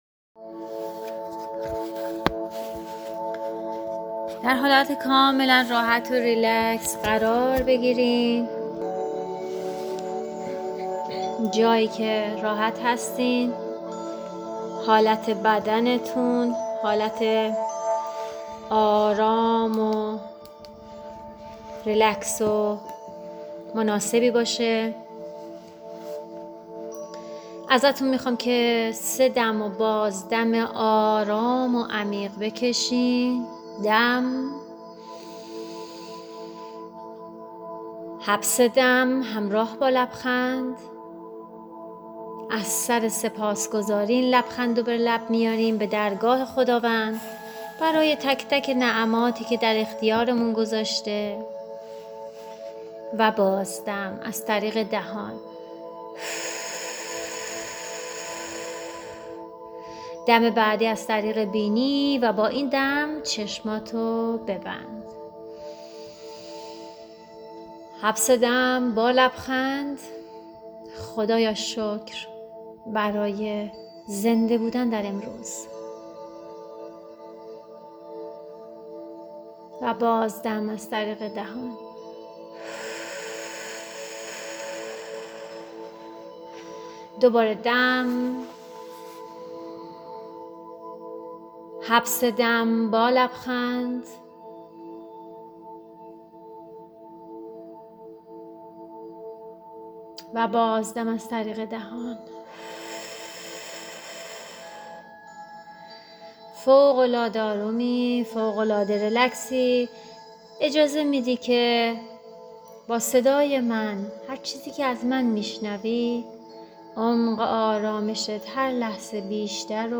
مراقبه تجربه یگانگی با نیروی عالم